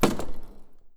impact_small.wav